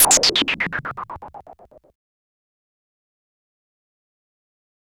Rock Star - Tape FX.wav